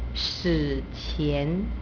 shi3-qian2= qian means before; before history is prehistoric.